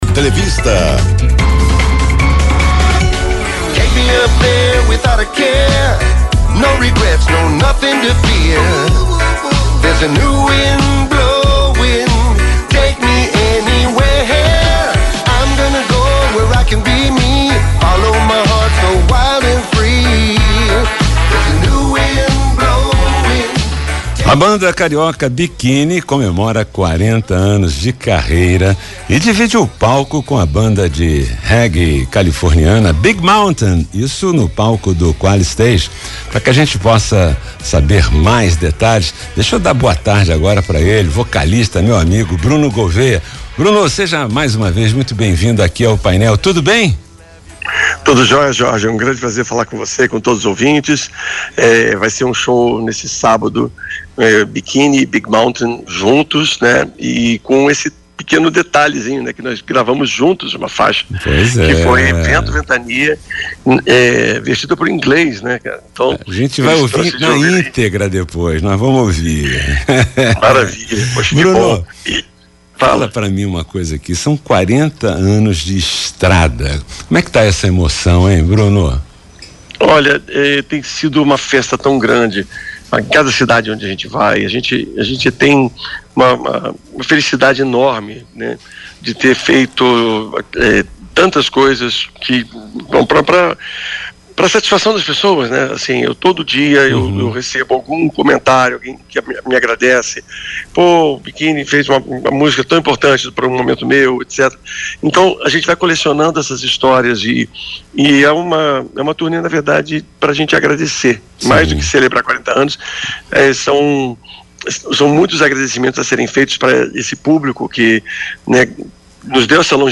entrevista BRUNO.mp3